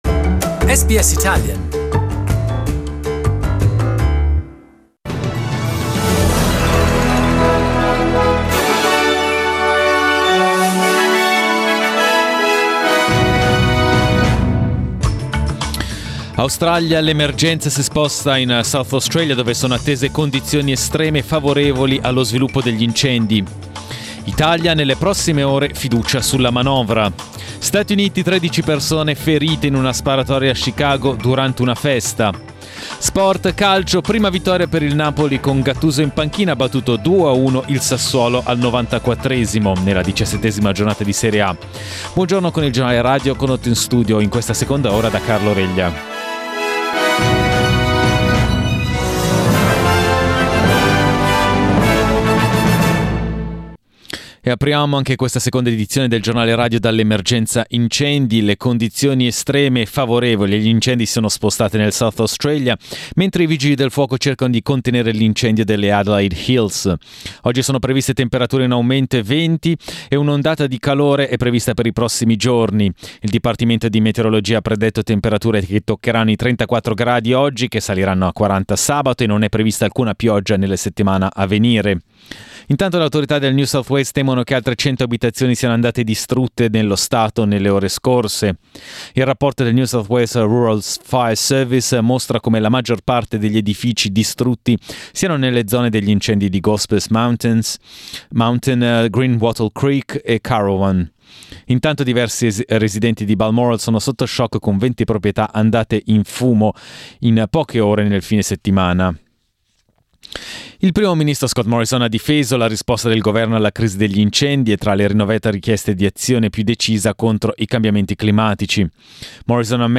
Our news bulletin (in Italian).
Giornale radio lunedì 23 dicembre.